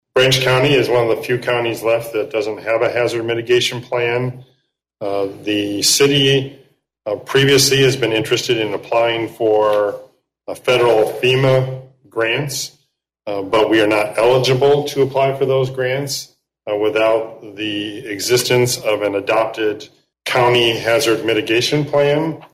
Coldwater City Manager Keith Baker said during last week’s City Council meeting the Plan defines a process for identifying, quantifying, and reducing risk in Branch County.